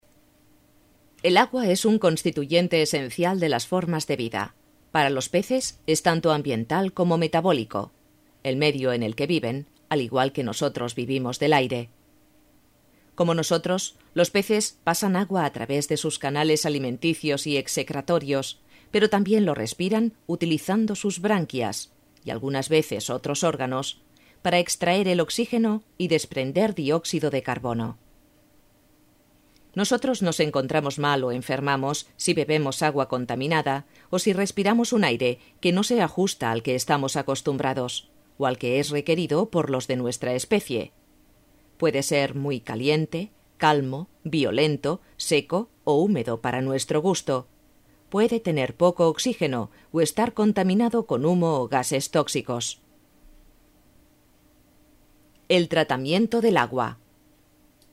Voz media y joven.
kastilisch
Sprechprobe: eLearning (Muttersprache):